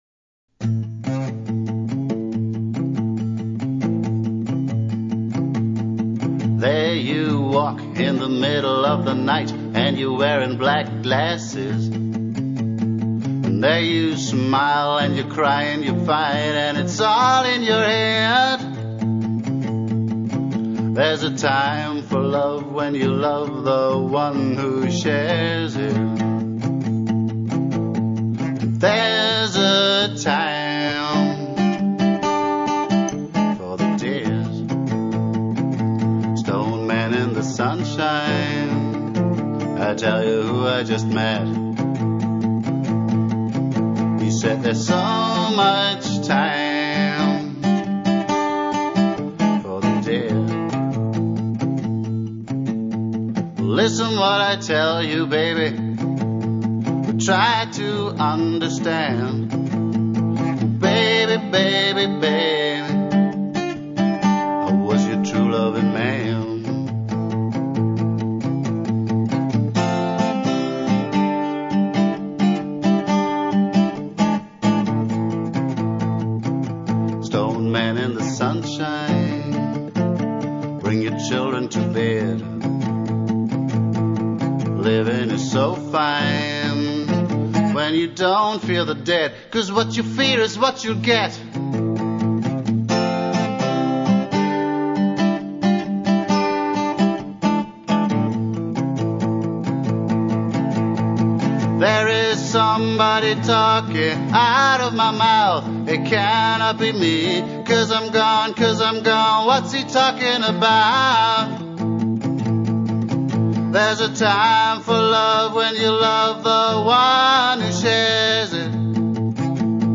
Rock'n'Roll, g + voc, Song # 57, mp3